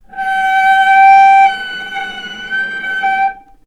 vc_sp-G5-ff.AIF